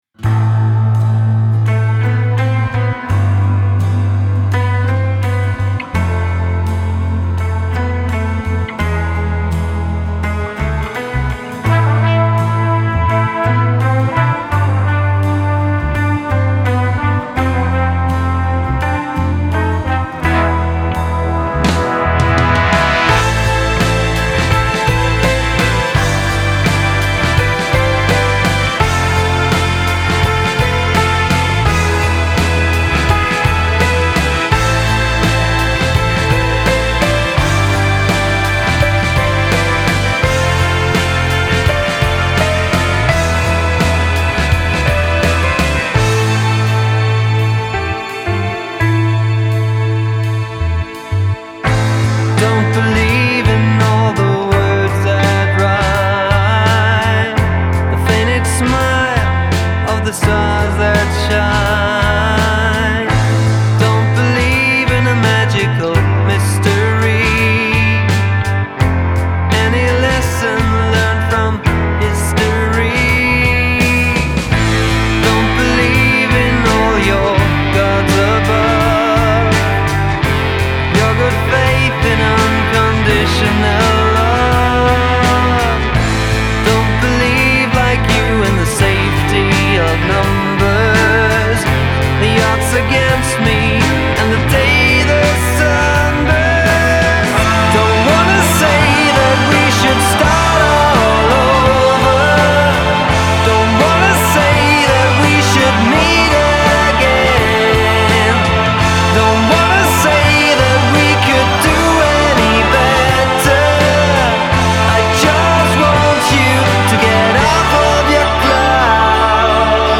Ist das Indie, ist das Pop?